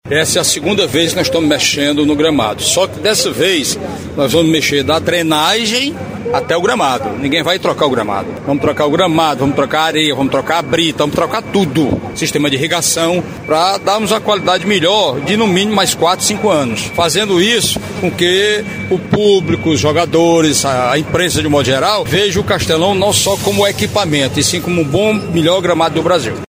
O Superintendente de Obras Públicas, Quintino Vieira, falou sobre a execução dos trabalhos, cujo objetivo é fazer do Castelão um dos melhores gramados do Brasil.